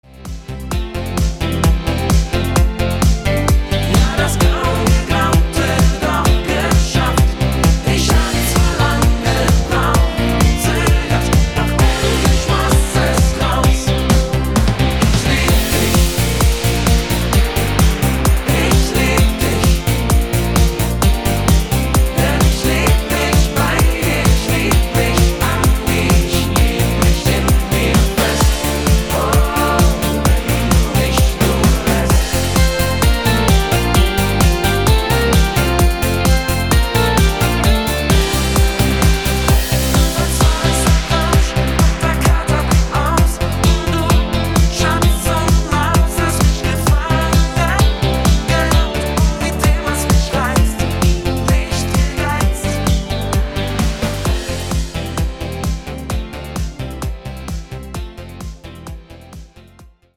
mit angenehmer Tonart C statt D
Rhythmus  Discofox
Art  Deutsch, ML Remix, Party Hits, Schlager 2020er